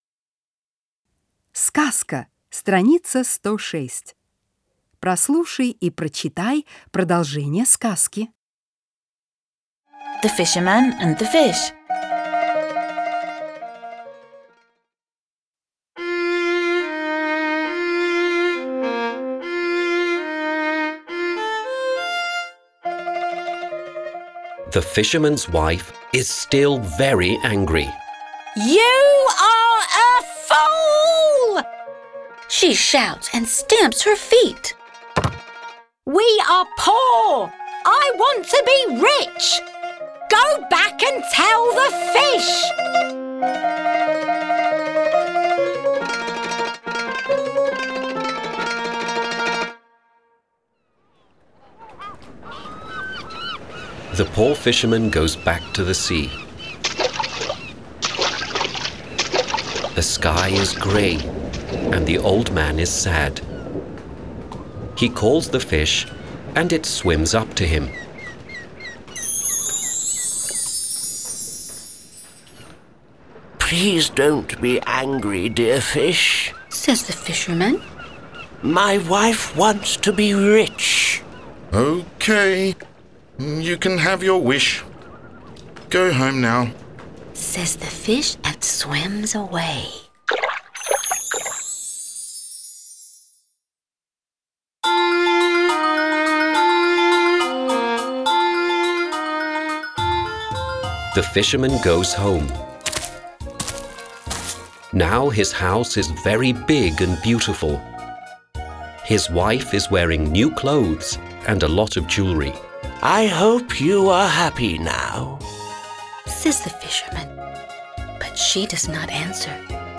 10-Сказка-с.-106-.mp3